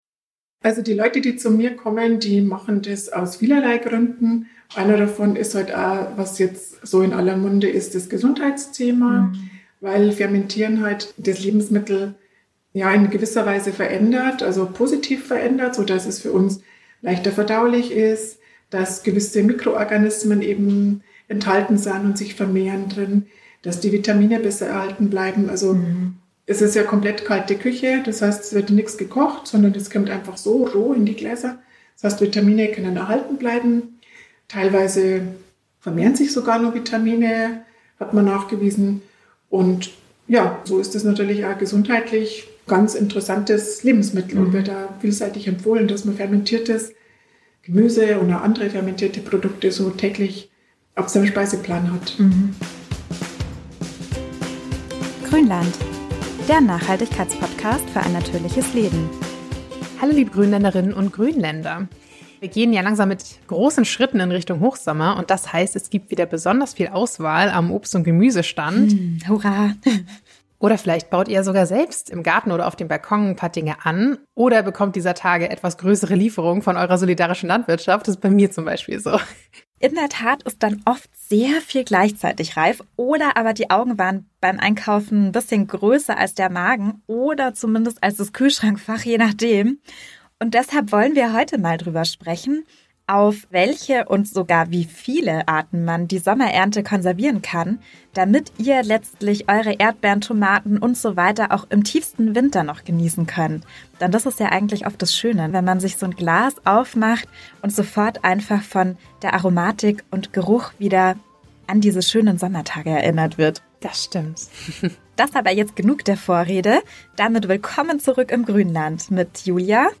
Wir erklären euch die gängigsten Methoden von Einkochen bis Dörren und holen uns im Interview Tipps